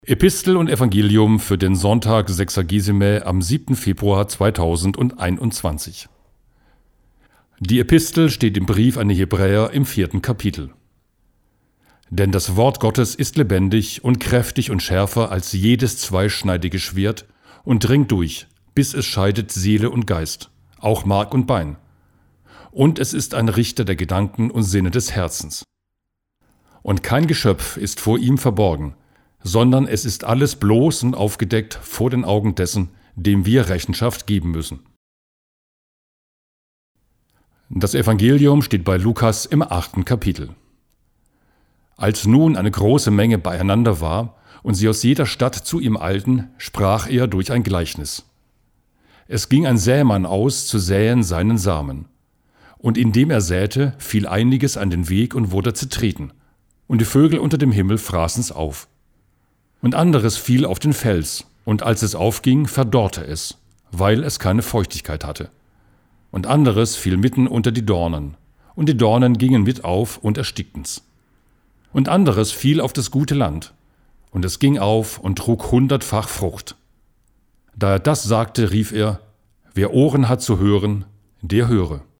Epistel und Evangelium